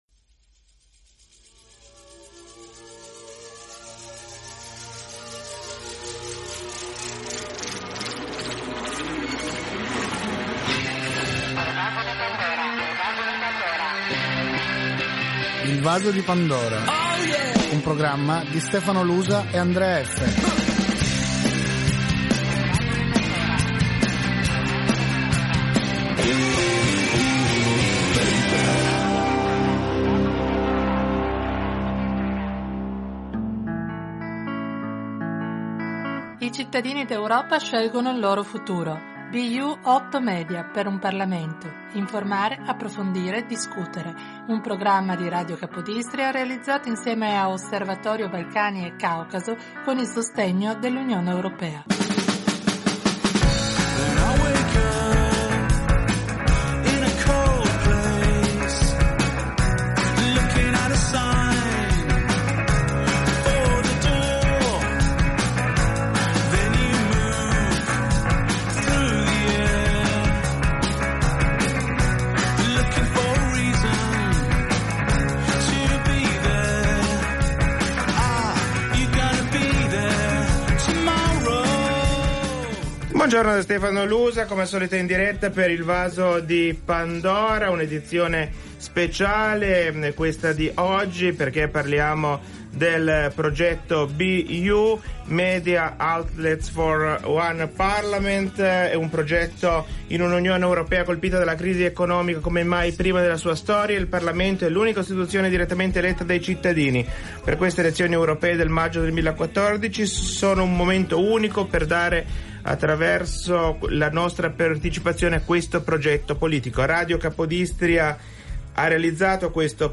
trasmissione di Radiocapodistria